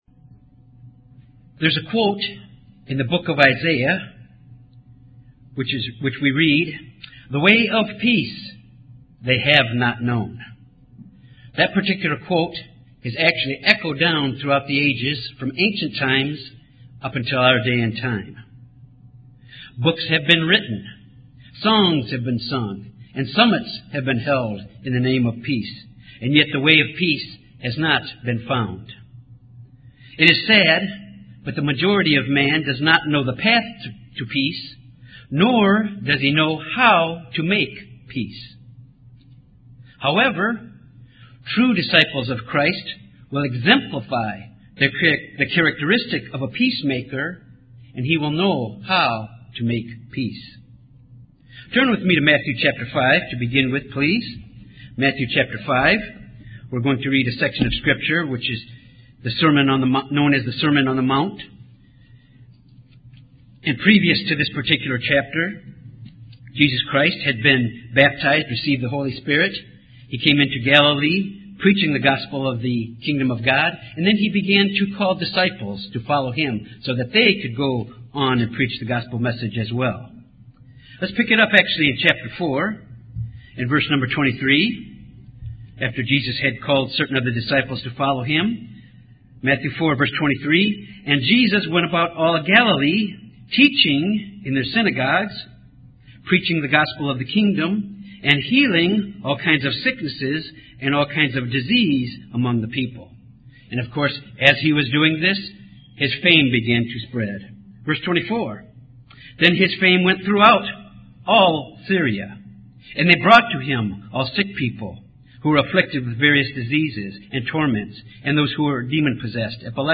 Jesus said blessed are the peacemakers for they shall be called the sons of God. In this sermon the walk of a peacemaker is examined
Given in Little Rock, AR